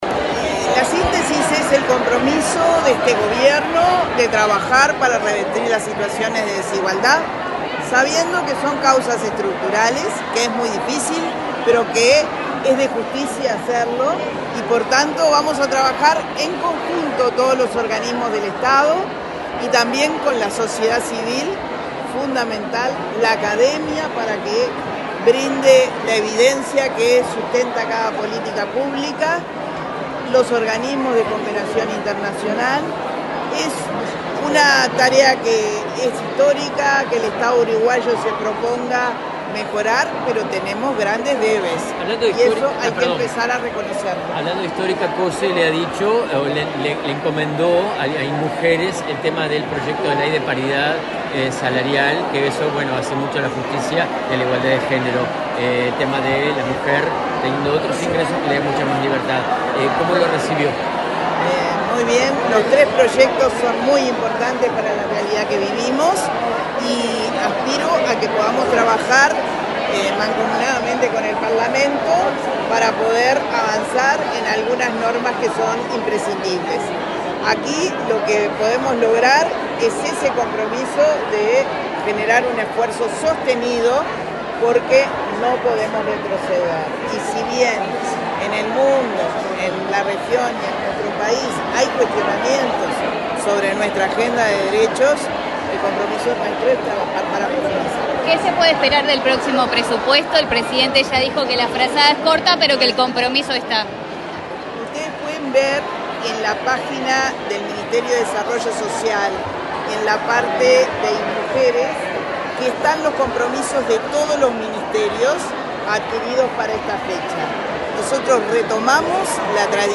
Declaraciones a la prensa de la directora de Inmujeres, Mónica Xavier
La directora del Instituto Nacional de las Mujeres (Inmujeres) del Ministerio de Desarrollo Social, Mónica Xavier, dialogó con la prensa, luego de